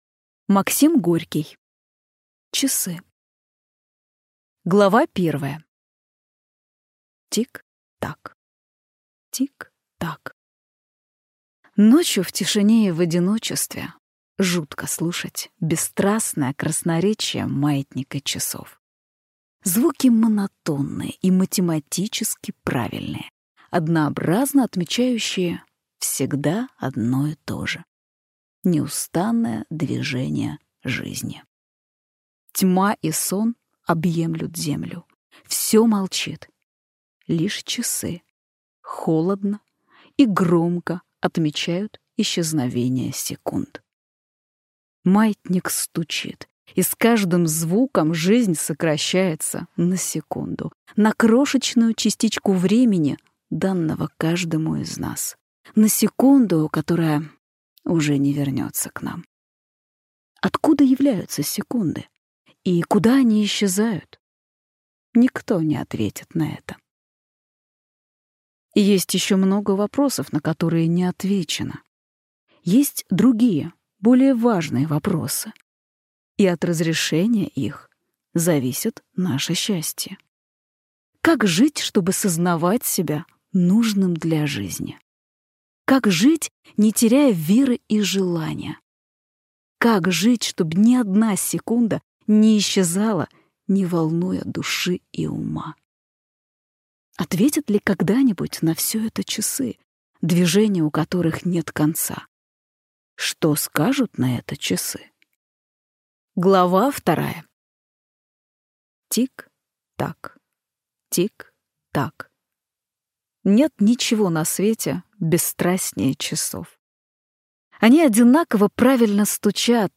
Aудиокнига Часы